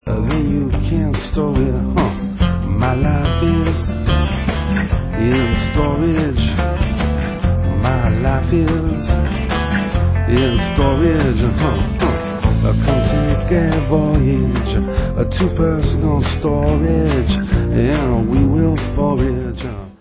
NEW 2005 STUDIO ALBUM